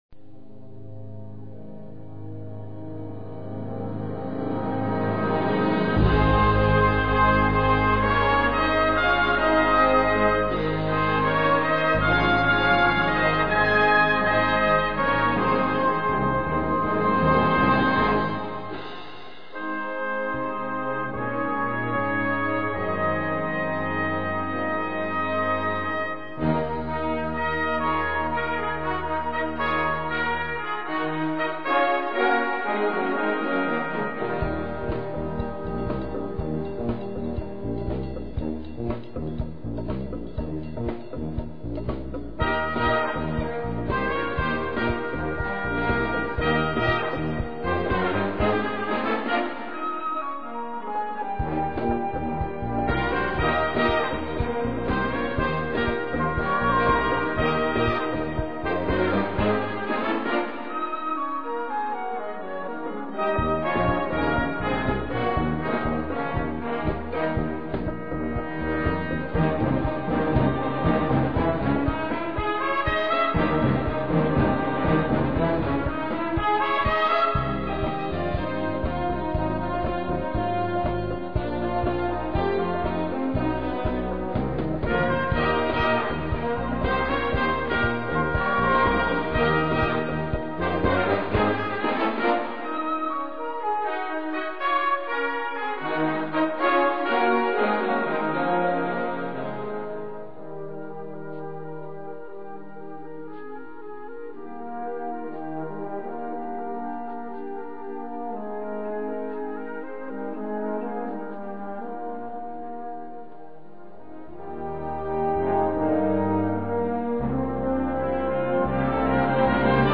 Gattung: Selection
Besetzung: Blasorchester